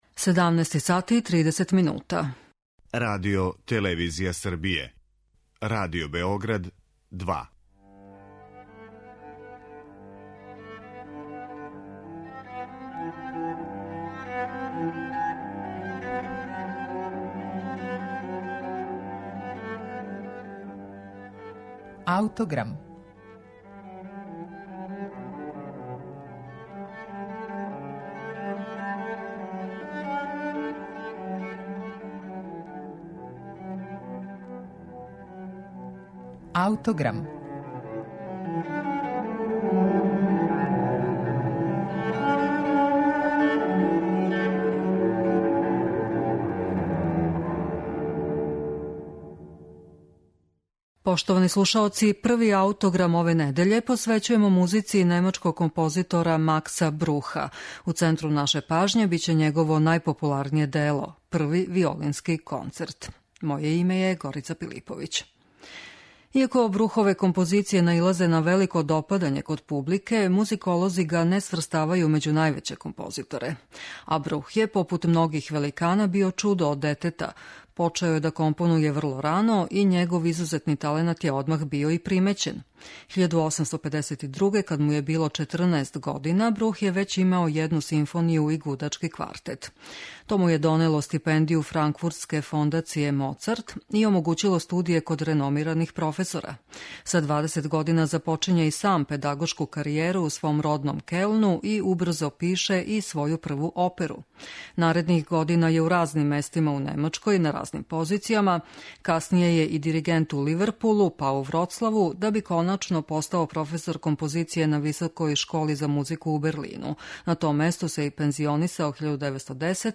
Можете слушати први Концерт за виолину и оркестар, Макса Бруха